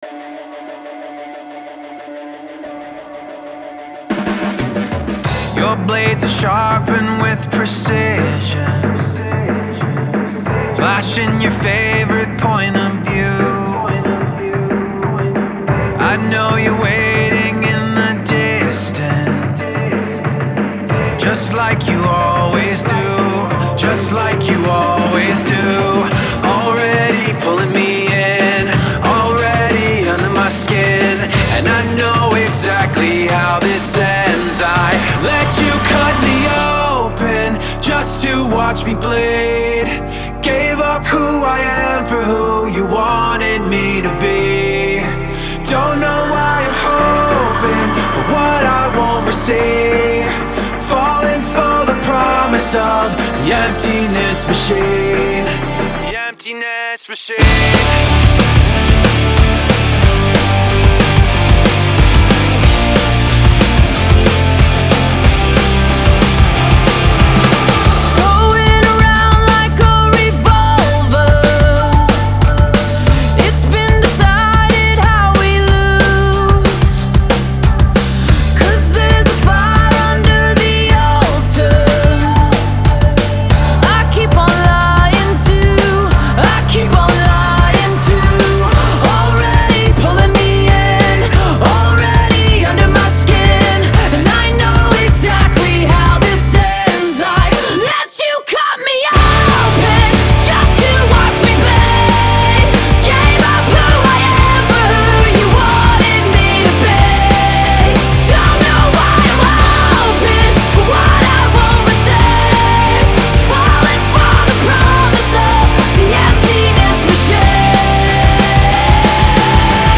alternative metal nu metal